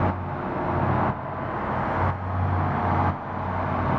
Index of /musicradar/sidechained-samples/120bpm